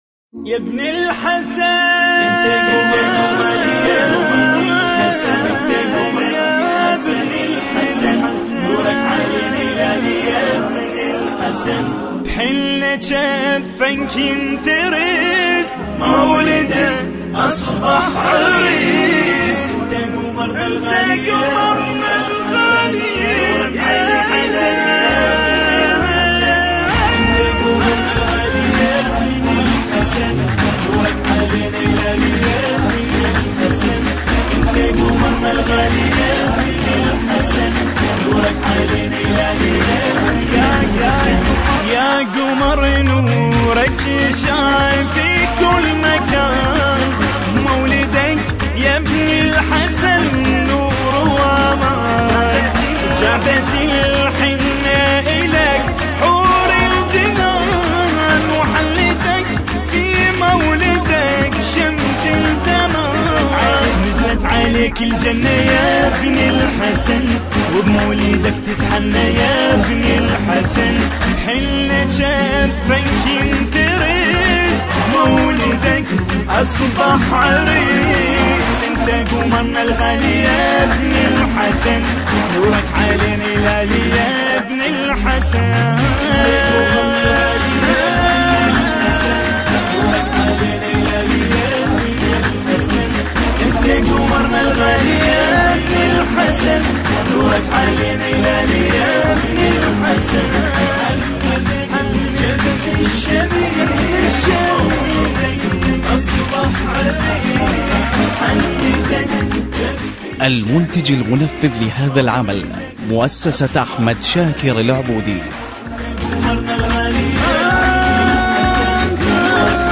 متن مولودی نیمه شعبان و تولد امام زمان (عج) + سرود صوتی شاد
مولودی نیمه شعبان عربی
diffrent-MADAHAN-molody-arabi-emam-zaman4.mp3